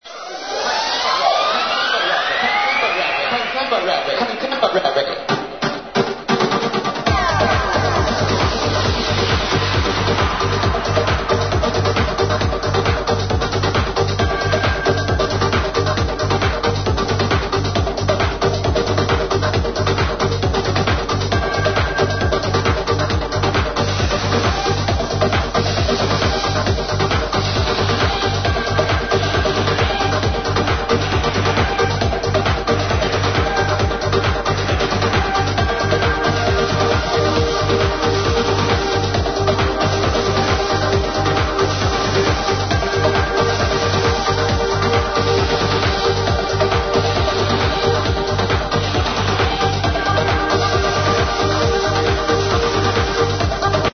Trance from circa 2001